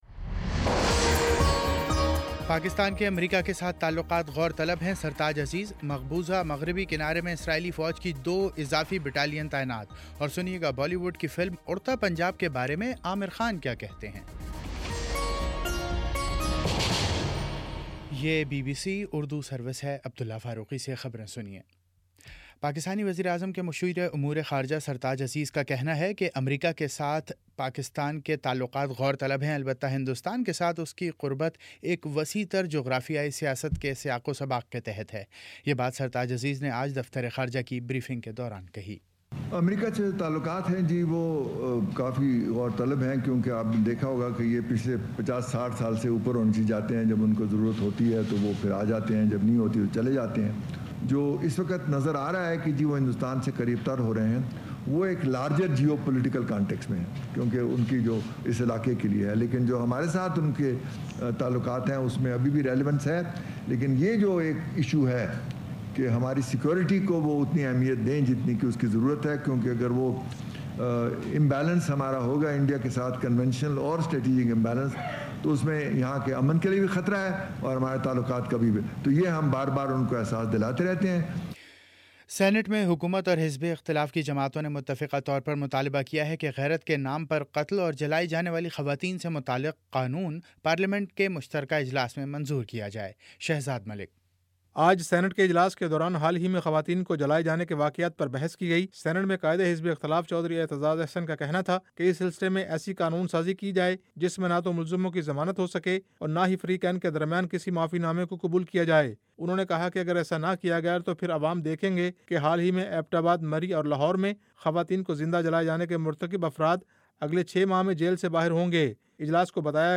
جون 09 : شام پانچ بجے کا نیوز بُلیٹن